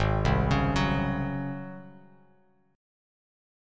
G#11 chord